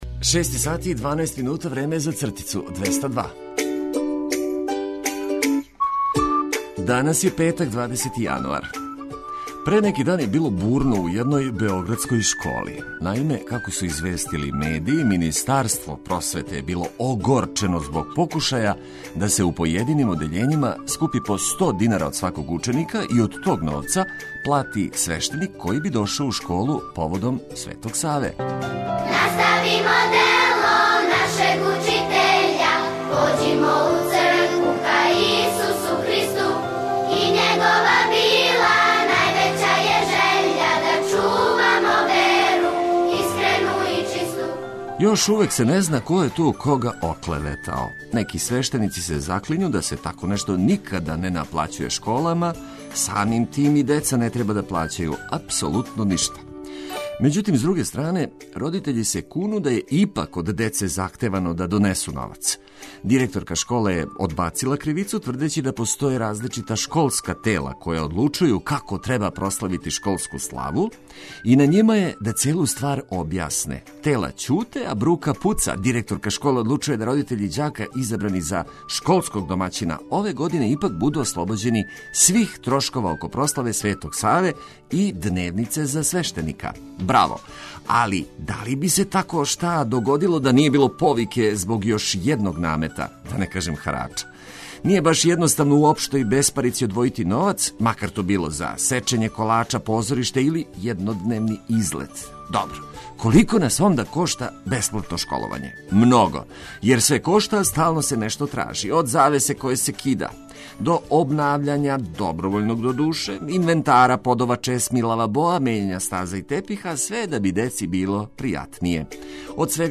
Будимо се овог јутра уз најлепшу музику за буђење и корисне информације унапред радујући се предстојећем викенду.